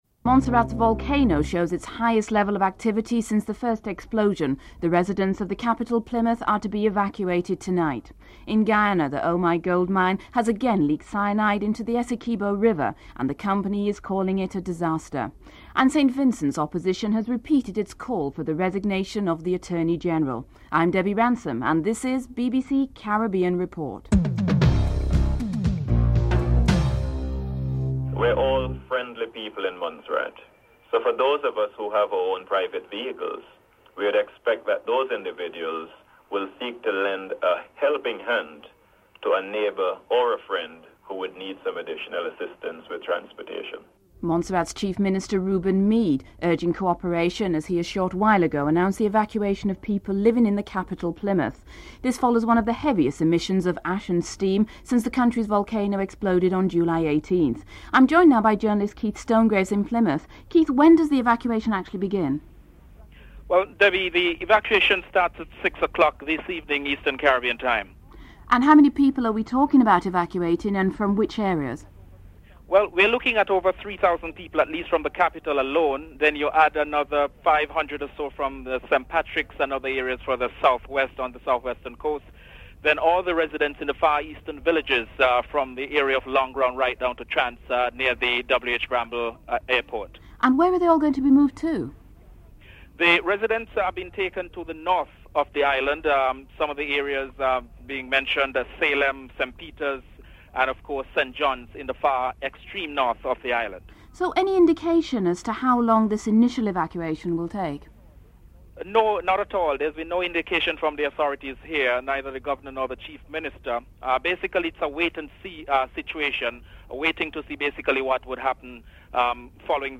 In this report Montserrat's Chief Minister, Reuben Meade, urges cooperation as the evacuation of people living in the capital Plymouth is announced. In Guyana, cyanide contaminated water has leaked into the Essequibo River from the Omai Gold Mines.
The Guyanese people on the streets of Georgetown were interviewed to get their reaction to the latest cyanide spill.